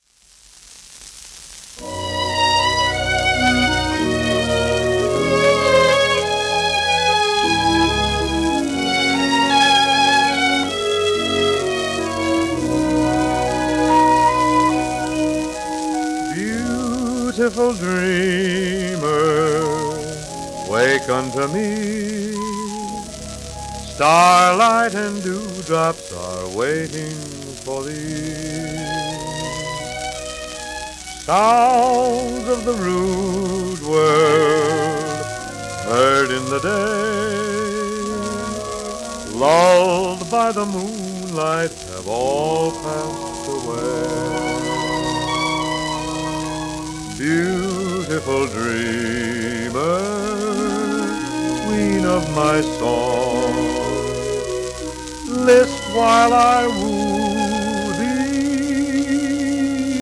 w/オーケストラ
盤質B+ *一部溝荒れ有
1940年録音